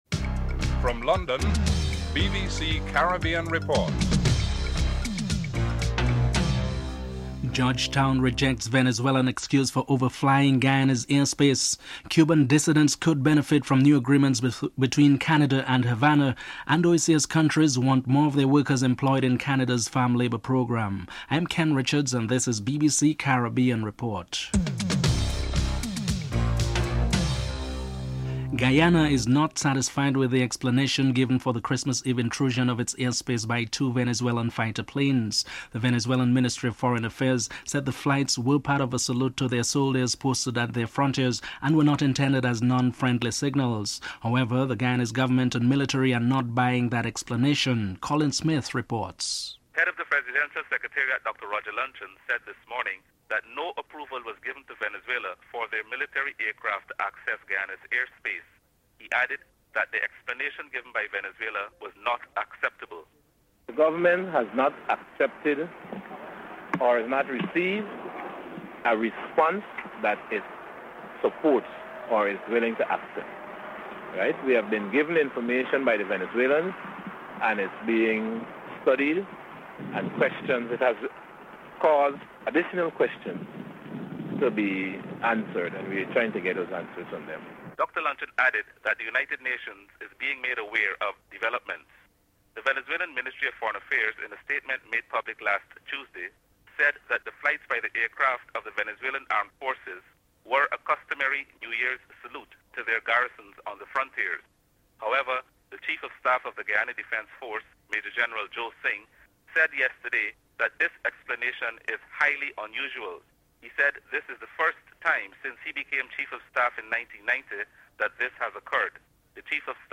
1. Headlines (00:00-00:27)
3. Forty years ago today Fidel Castro triumphantly entered Havana. Robert McNamara, former US Secretary of Defense and one of the growing numbers of influential Americans arguing the case for the lifting of the American embargo, speaks. Some Cubans also share their views about Castro (02:10-05:57)